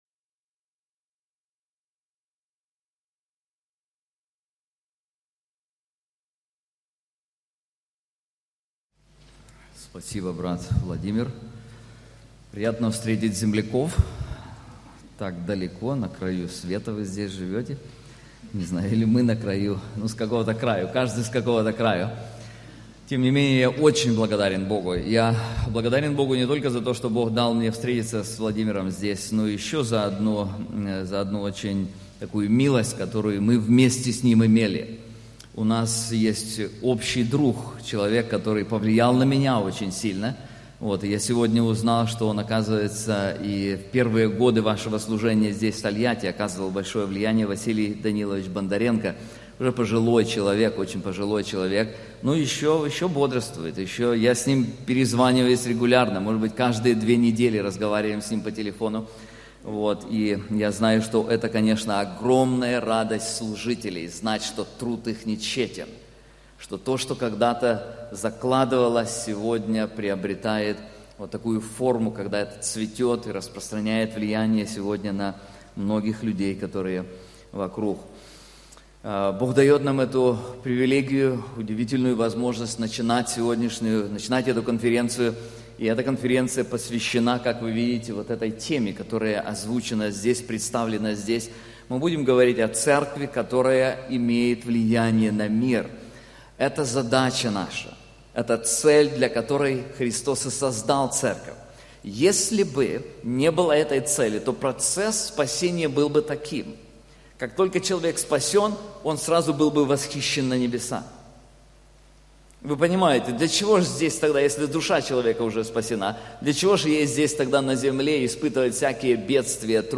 Конференции Церковь, изменяющая мир